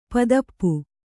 ♪ padappu